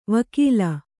♪ vakīla